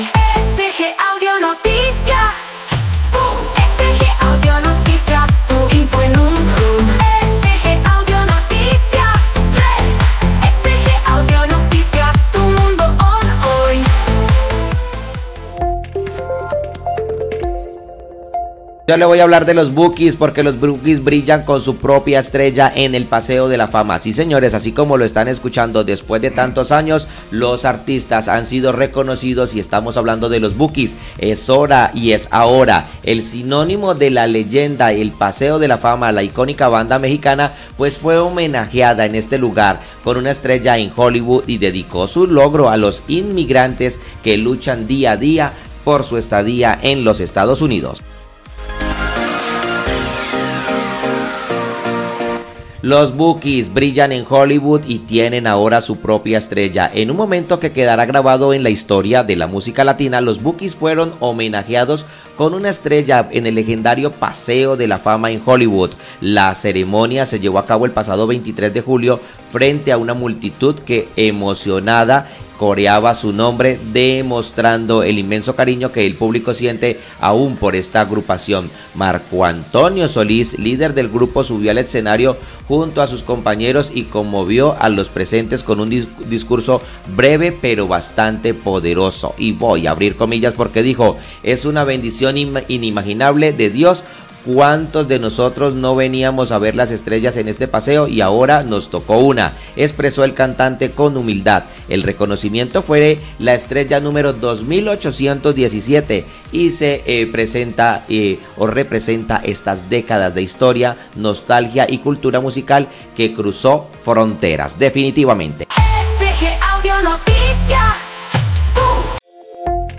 SG AudioNoticias